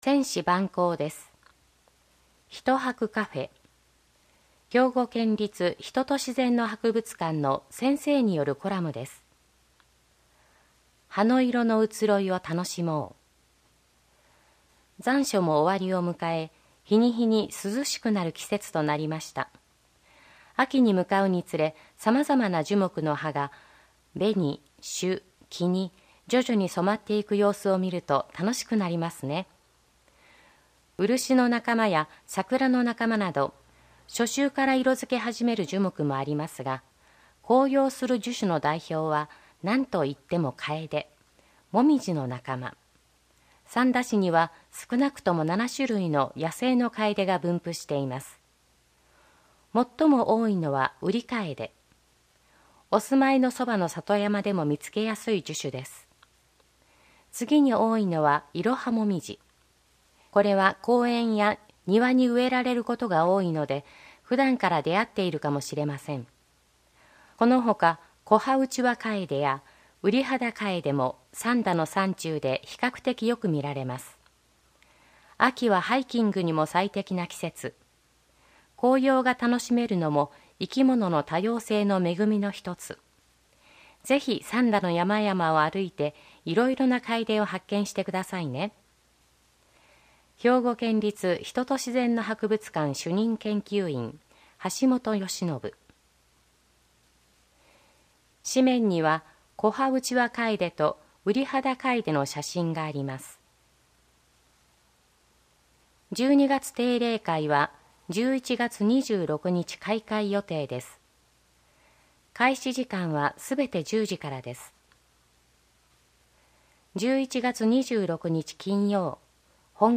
議会だより「つなぐ」掲載内容の音声データを、項目ごとに分けて配信しています。